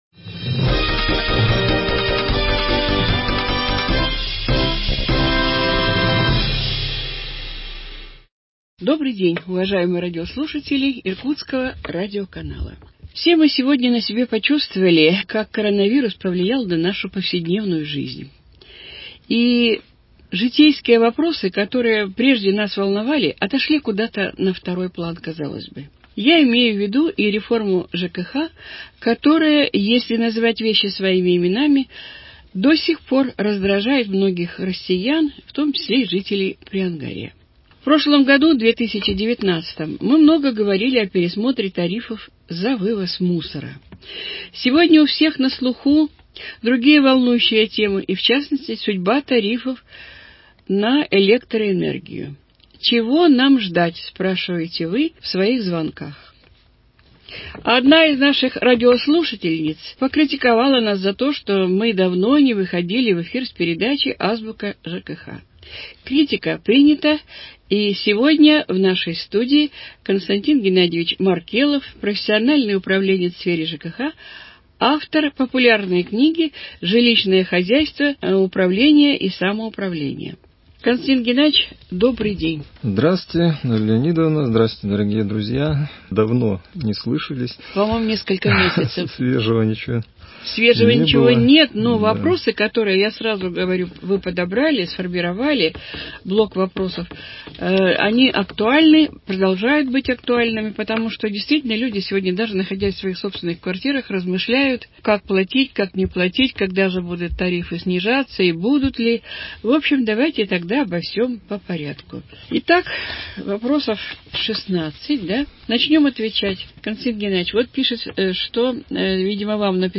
Передача «Азбука ЖКХ» 1 часть. Формат передачи «Вопрос – Ответ».